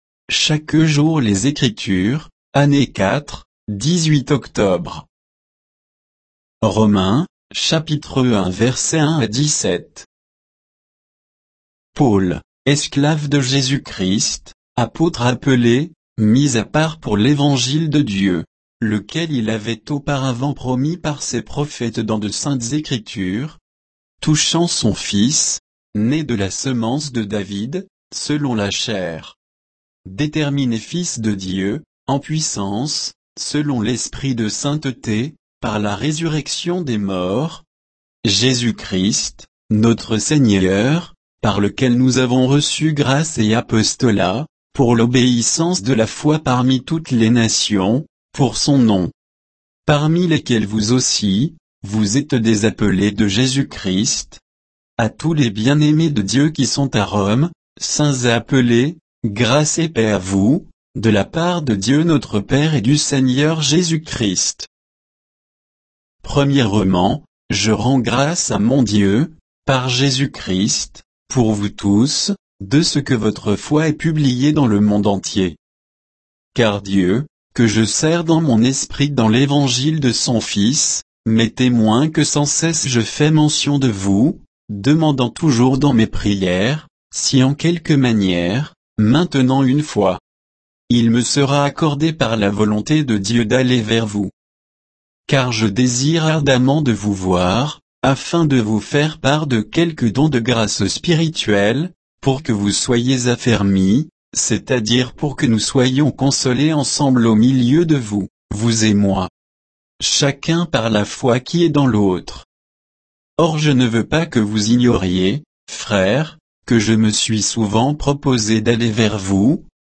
Méditation quoditienne de Chaque jour les Écritures sur Romains 1, 1 à 17